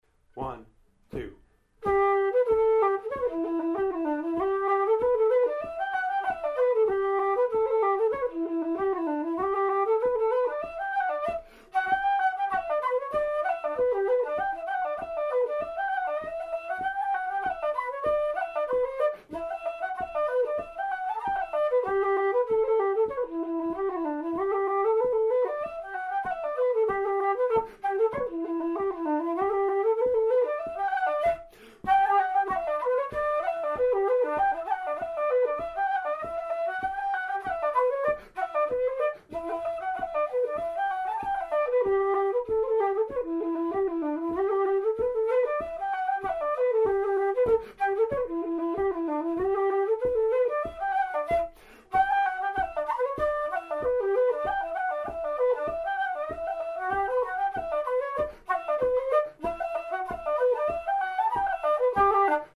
2016 Reels  Recordings  Sheet Music